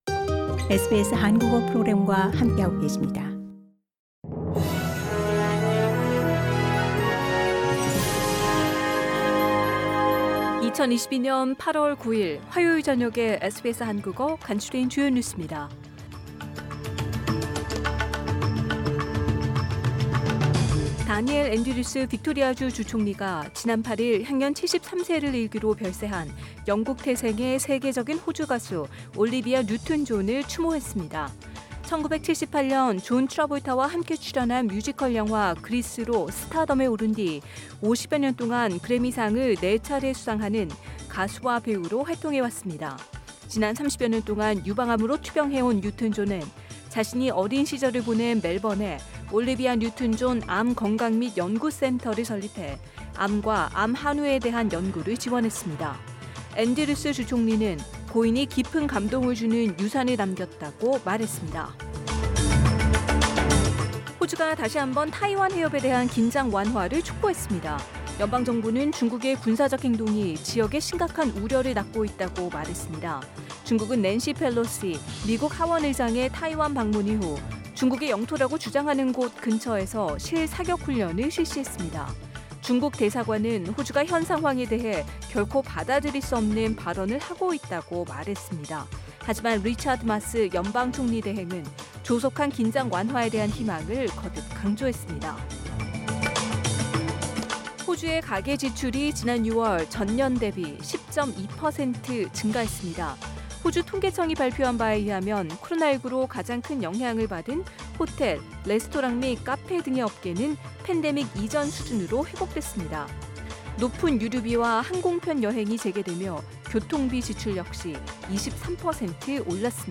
SBS 한국어 저녁 뉴스: 2022년 8월 9일 화요일